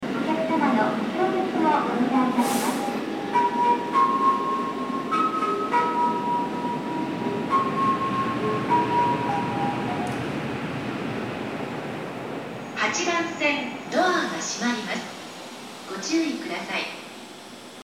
那須塩原駅　Nasushiobara Station ◆スピーカー：ユニペックス小型,ユニペックス小丸型
8番線下り発車メロディー